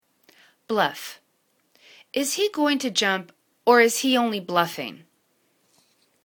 bluff    /bluf/    v